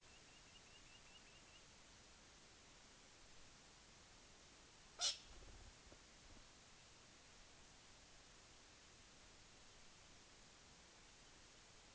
More ‘mystery’ calls
Here are a few more ‘mysteries’ that I’ve extracted from the Sturt National Park analysis of 29 June 2012.
All I can think of for this is an alarmed Rufous Night-heron (Xeno-Canto Link)
Could the first call be the short, sharp raspy call of an annoyed Galah? That’s what it sounds like to me.